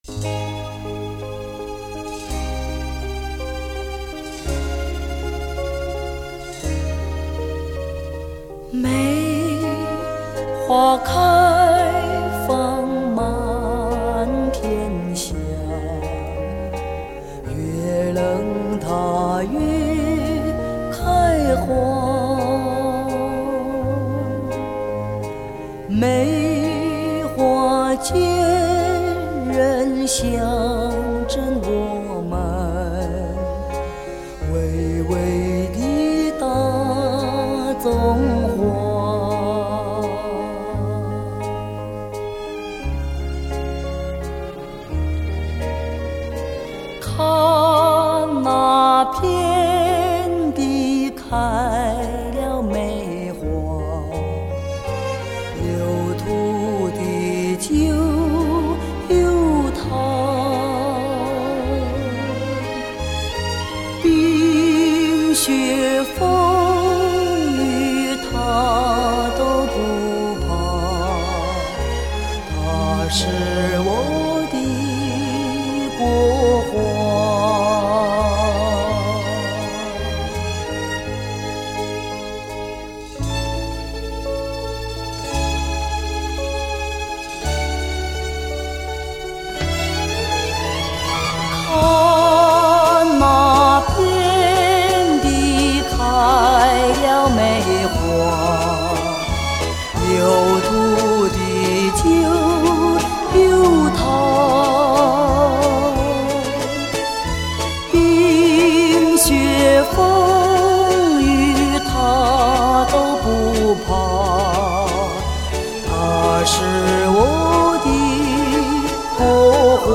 回味从小到大的磁性声音 惟有黑胶原版CD